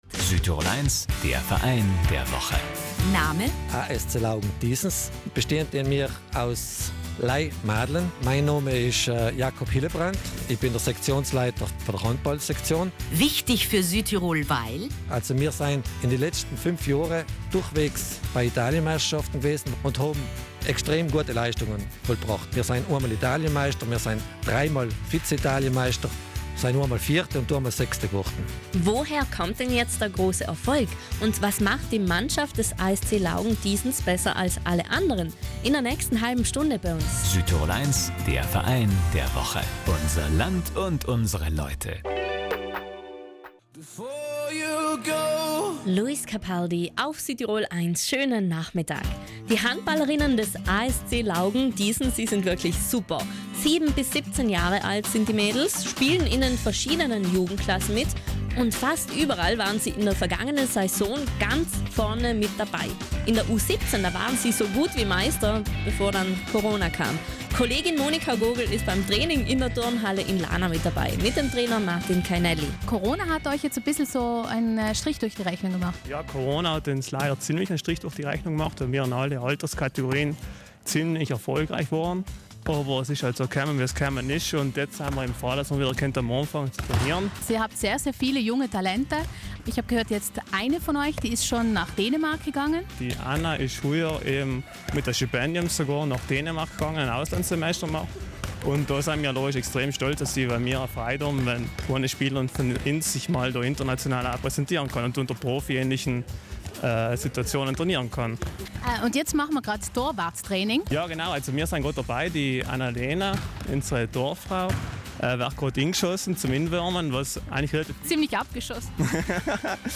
Wir haben den Verein besucht.